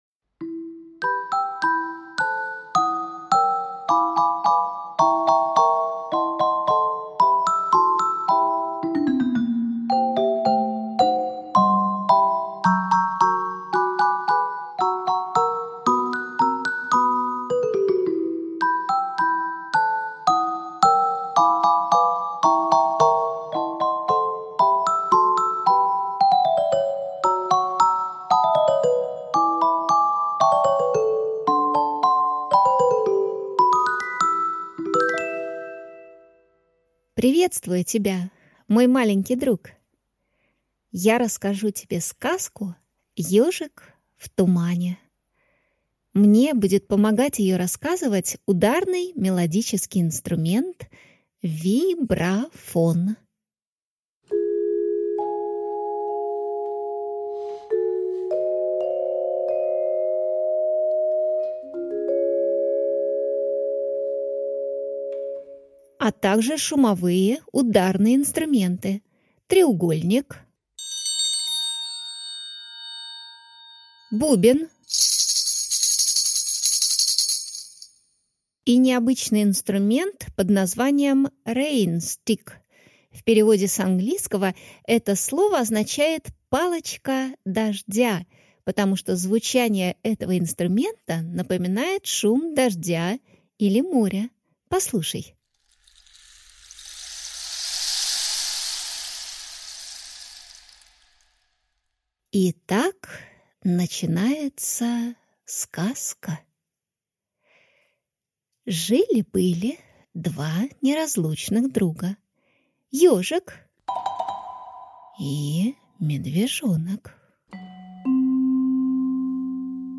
Aудиокнига Ёжик в тумане, или тишина в тишине Автор Ольга Пикколо. Прослушать и бесплатно скачать фрагмент аудиокниги